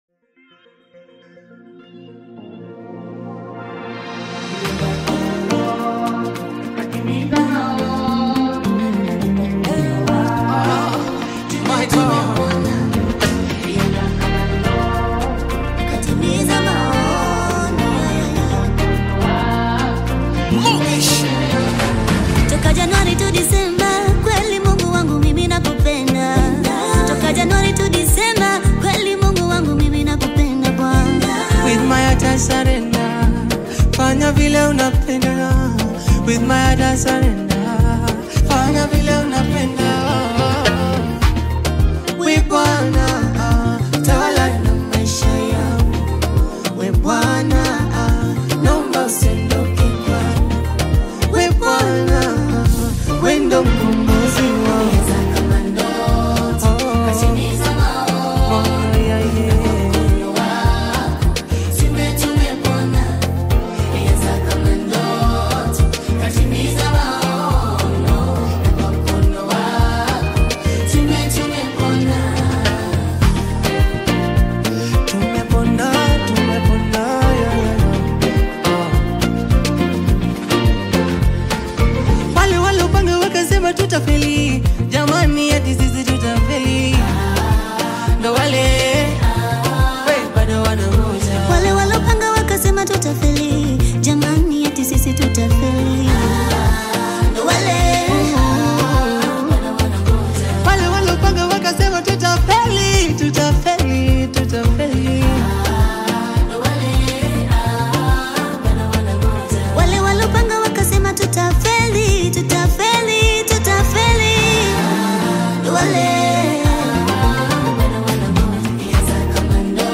moving Tanzanian gospel single
worship group
powerful Swahili worship lyrics
blends soulful harmonies and inspiring gospel melodies